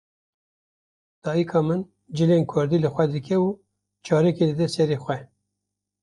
/sɛˈɾɛ/